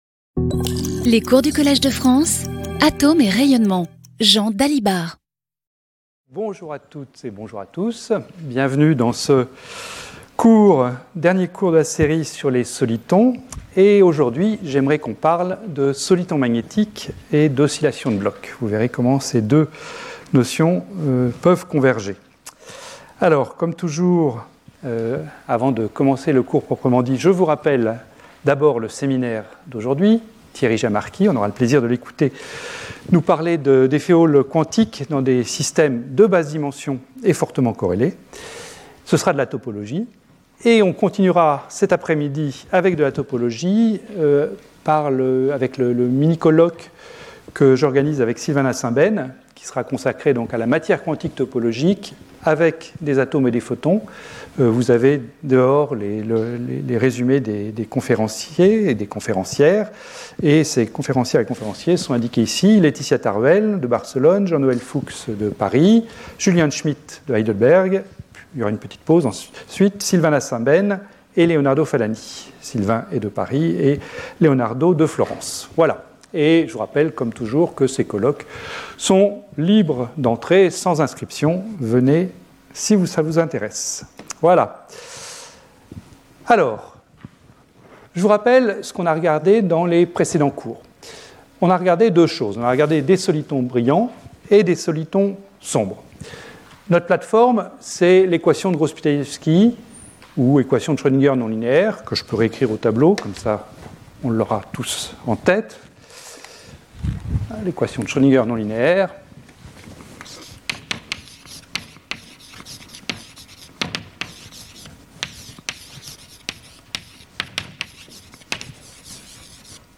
Intervenant(s) Jean Dalibard Professeur du Collège de France